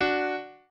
piano5_5.ogg